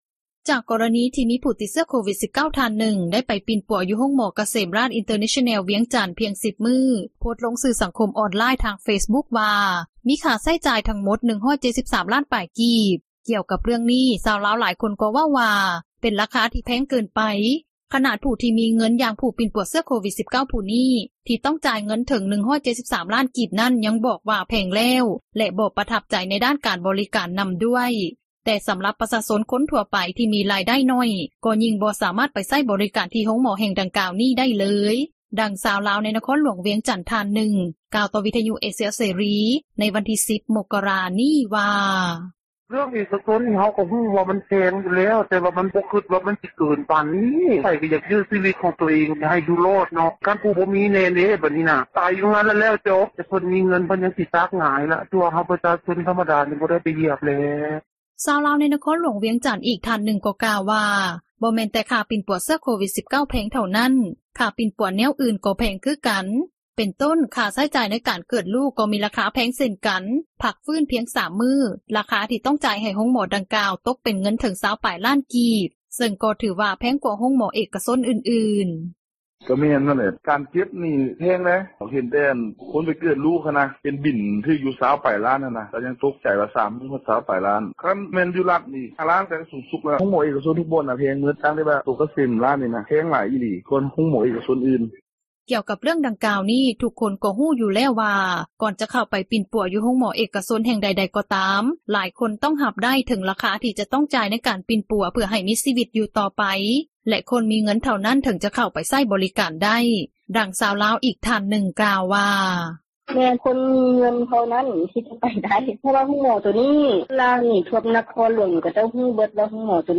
ດັ່ງຊາວລາວ ໃນນະຄອນຫຼວງວຽງຈັນ ທ່ານນຶ່ງ ກ່າວຕໍ່ວິທຍຸເອເຊັຽເສຣີ ໃນວັນທີ່ 10 ມົກກະຣາ ນີ້ວ່າ:
ດັ່ງຊາວລາວອີກທ່ານນຶ່ງ ກ່າວວ່າ: